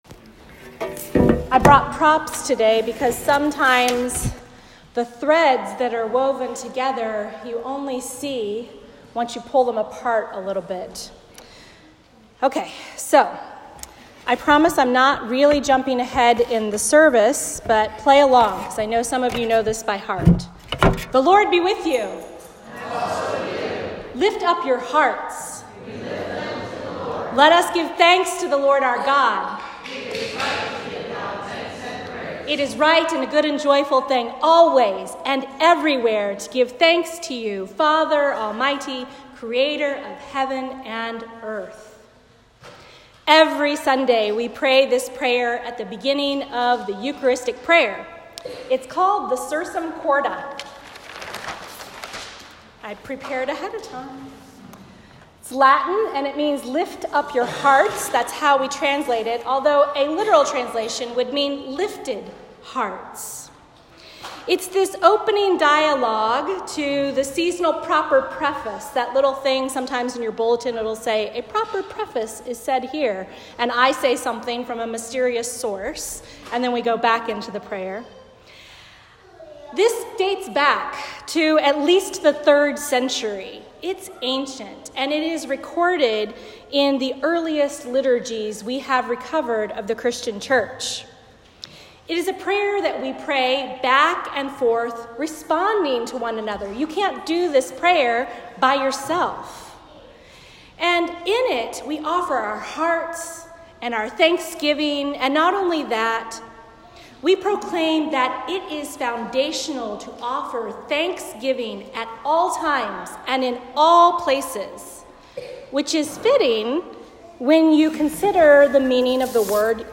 A Sermon for the Eighteenth Sunday After Pentecost